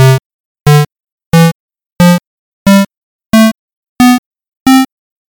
Therefore, instead of randomly choosing any note on the piano keyboard, what if your music only chose notes from a particular scale or chord? Immediately the music will start to make sense.